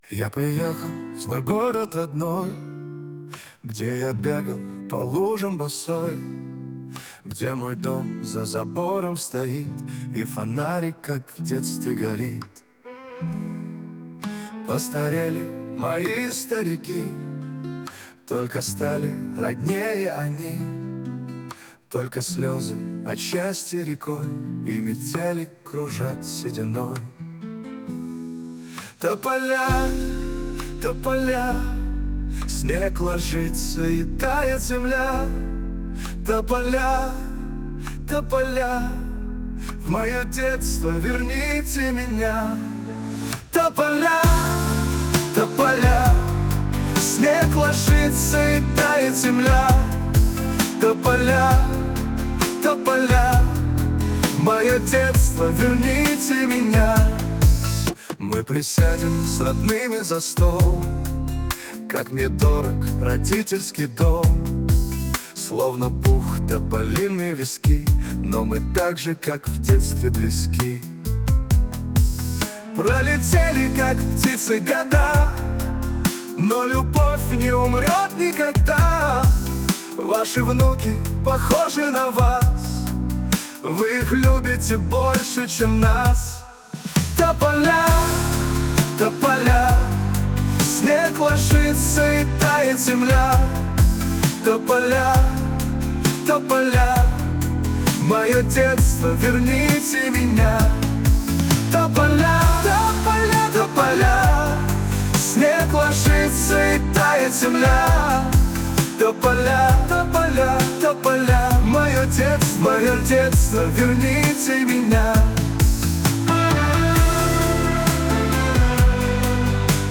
кавер-версия 2024 г.